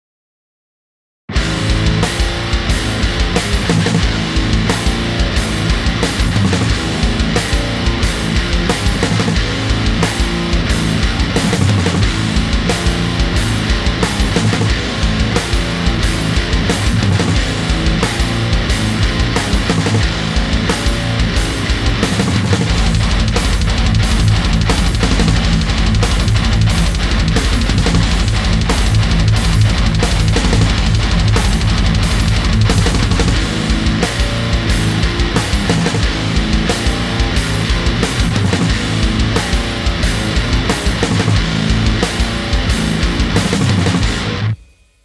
На записи только 2 гитары и барабаны, и все. Баса нет. Сведение и мастеринг на скорую руку.